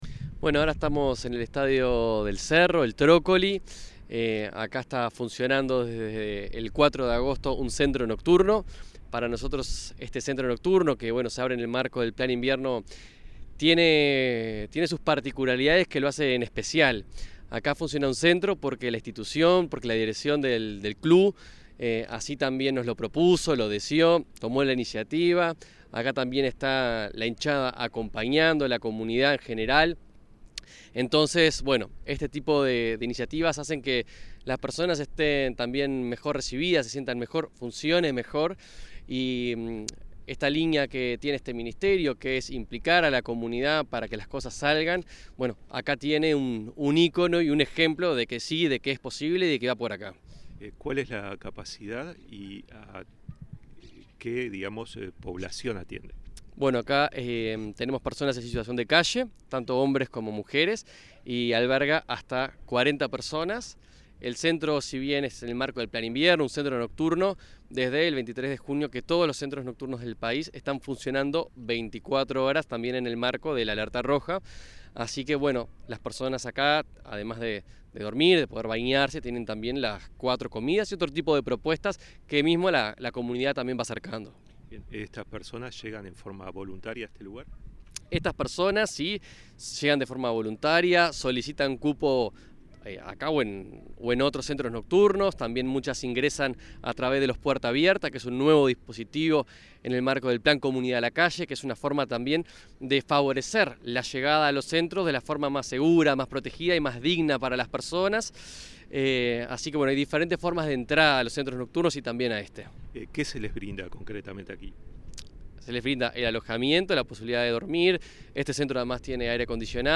Declaraciones del director nacional de Protección Social, Daniel Gerhard